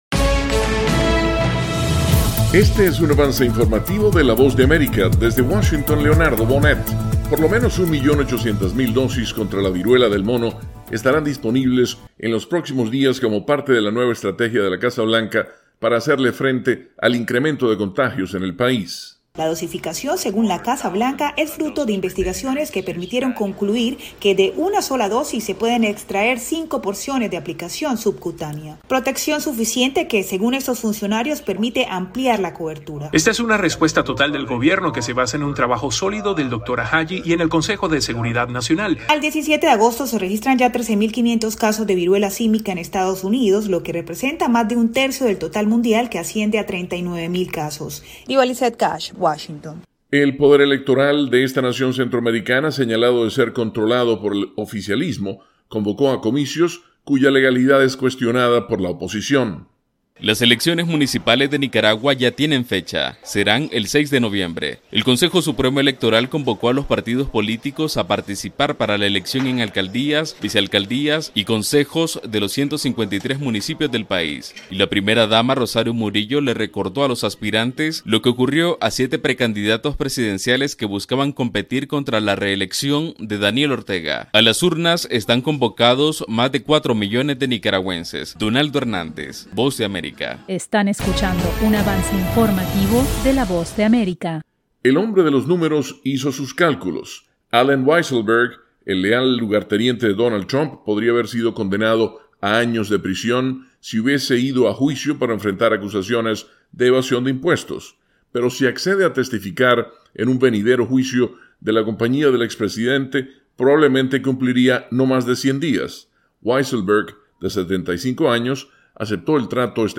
El siguiente es un avance informativo presentado por la Voz de América